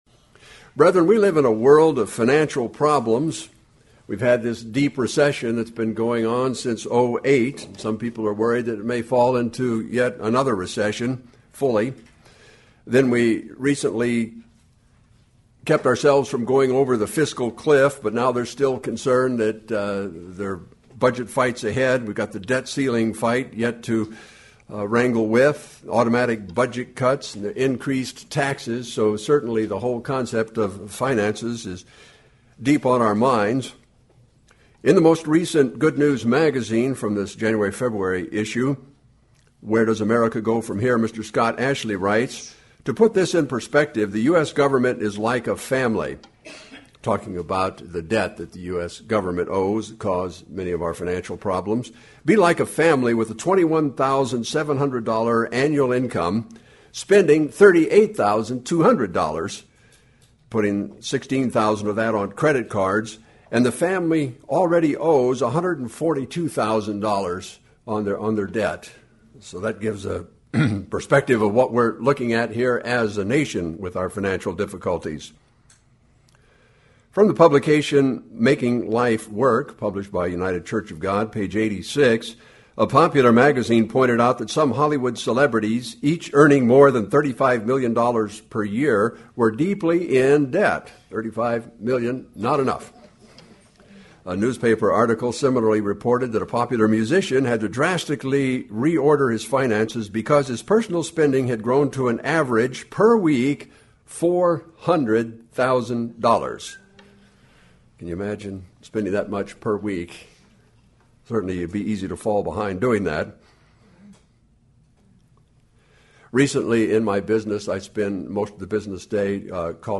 Given in Lawton, OK
UCG Sermon Studying the bible?